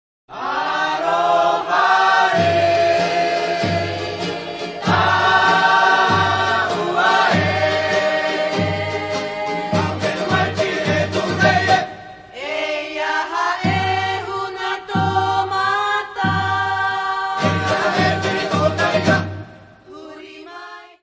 Music Category/Genre:  World and Traditional Music